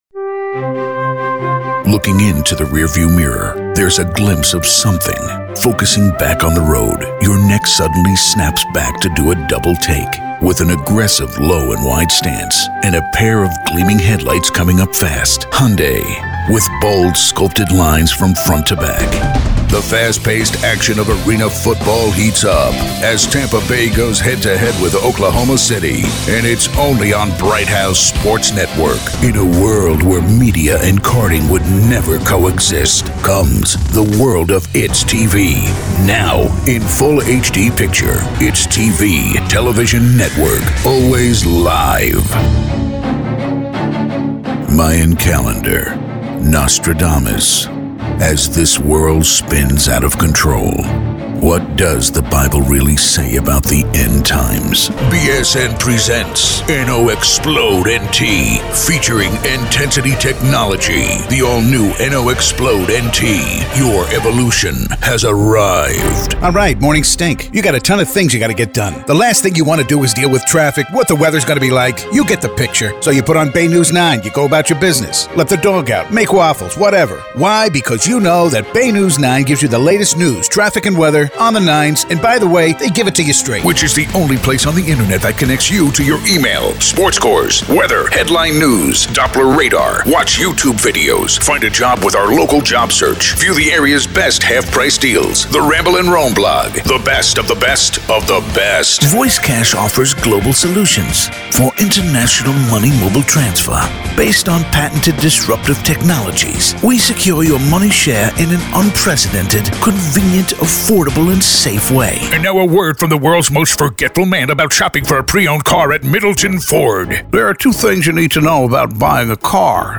Deep, Epic, Motivational.
Commercial